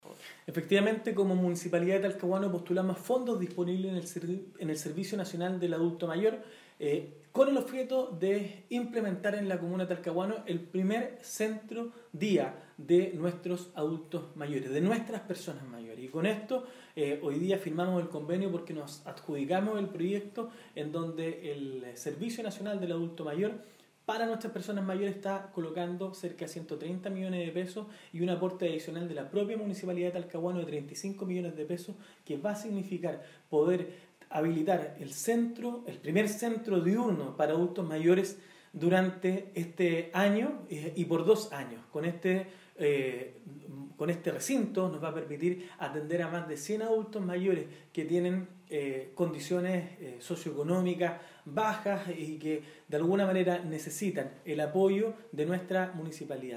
“Nos adjudicamos este proyecto y tendremos el primer centro diurno de la Región», expresó el Alcalde de la comuna puerto, Henry Campos Coa, argumentando que irá en beneficio de las personas con mayor vulnerabilidad y que necesitan atención médica, psicosocial y alimentaria.
02-alcalde.mp3